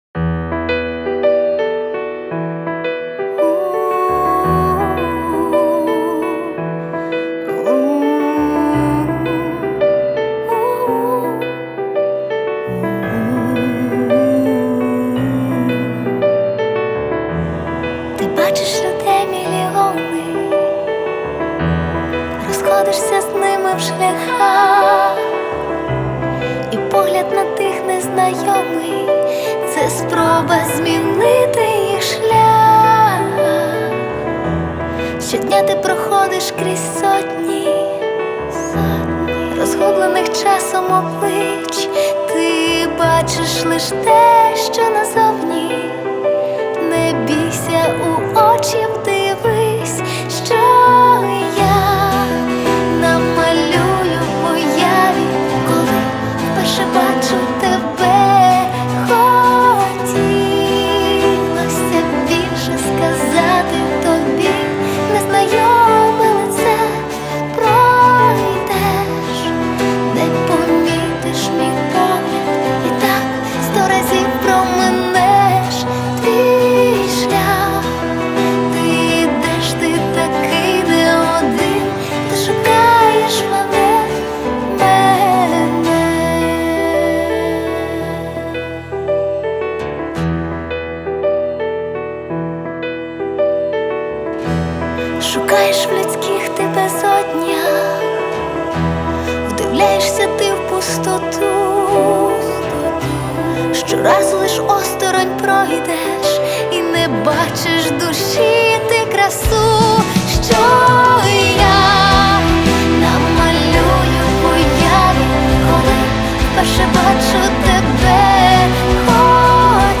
Новинки 2016 Украинская музыка